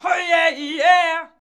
OHYEAHIY 2.wav